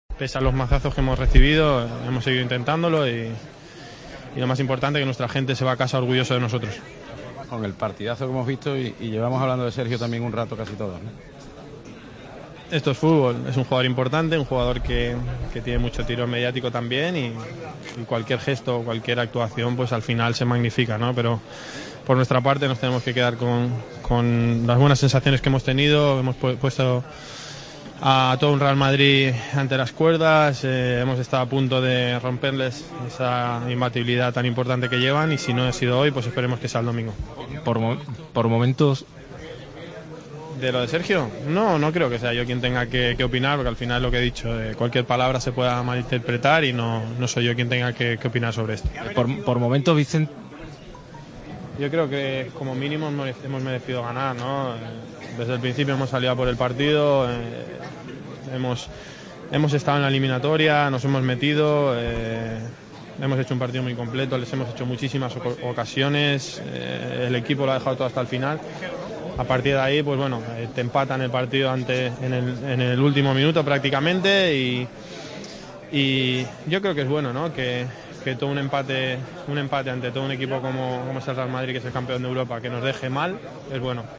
Sergio Ramos es un jugador con mucho tirón mediático", comentó el centrocampista del Sevilla, en zona mixta.